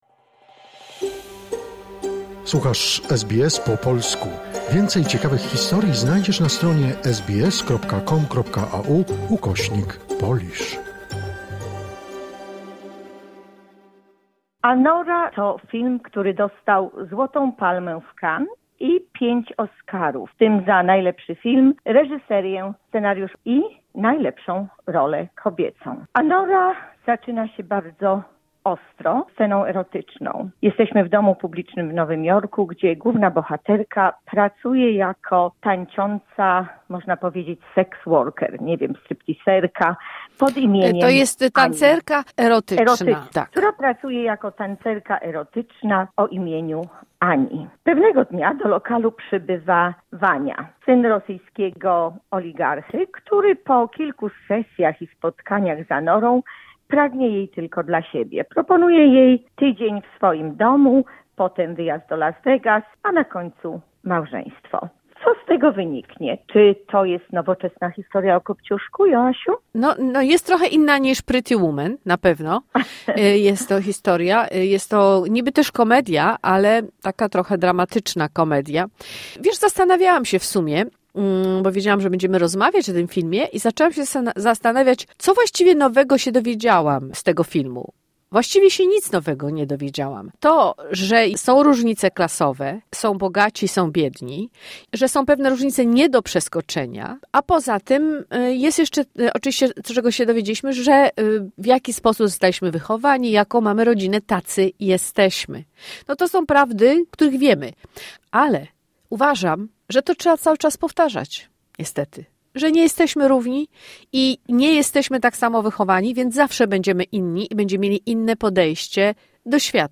"Anora"- recenzja filmowa